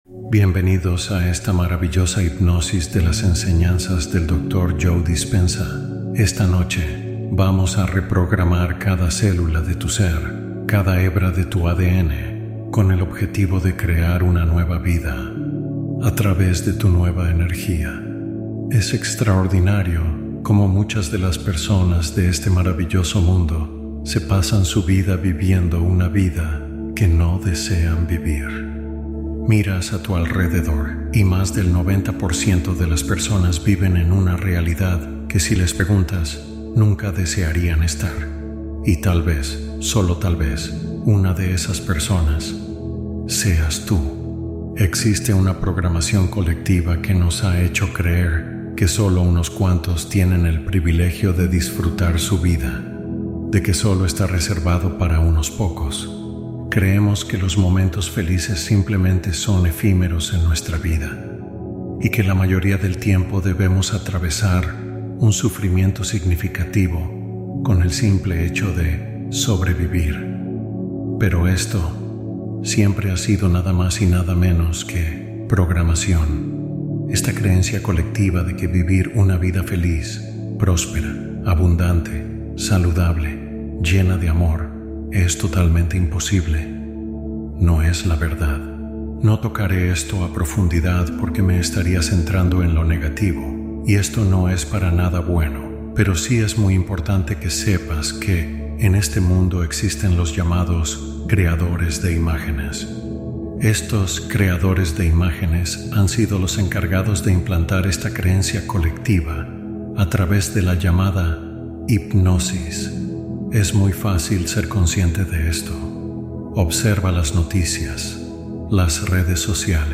Hipnosis transformadora centrada en gratitud y apertura personal